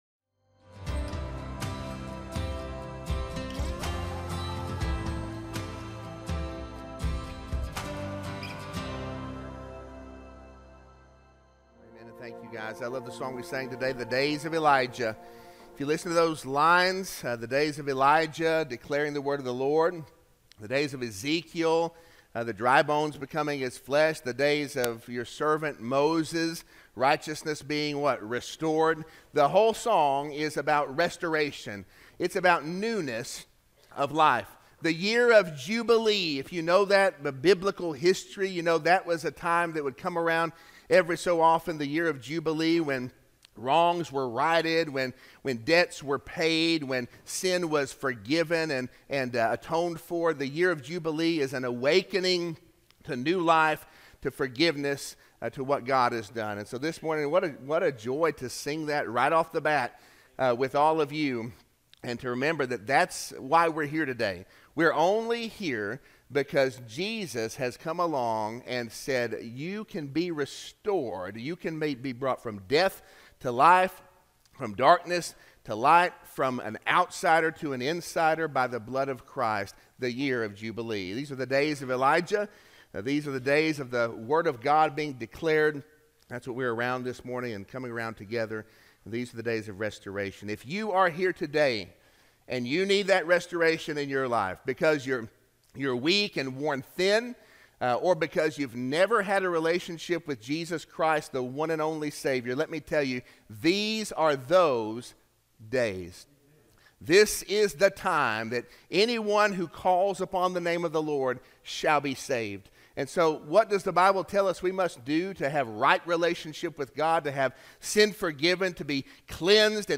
Sermon-2-23-25-audio-from-video.mp3